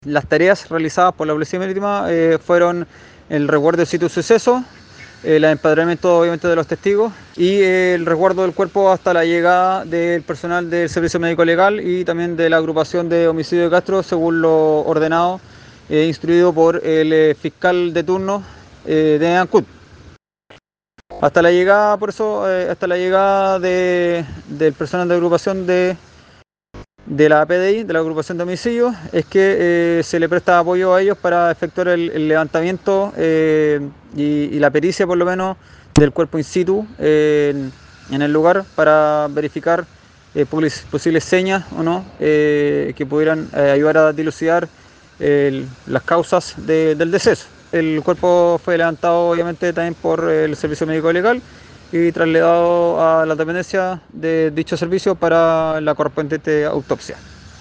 El oficial naval agregó otros datos respecto de este fatal incidente, donde lamentablemente se debió proceder a levantar el cuerpo ya fallecido de esta joven mujer.